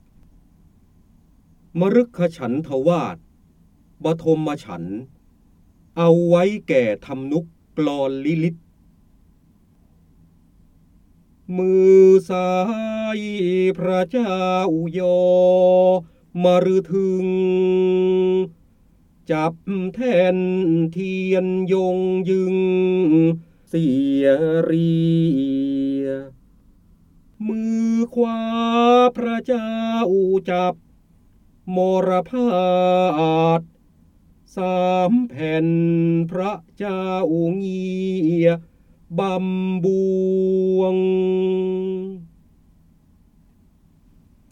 เสียงบรรยายจากหนังสือ จินดามณี (พระโหราธิบดี) มฤคฉันทวาศ บทมฉันท
คำสำคัญ : การอ่านออกเสียง, ร้อยกรอง, พระโหราธิบดี, ร้อยแก้ว, พระเจ้าบรมโกศ, จินดามณี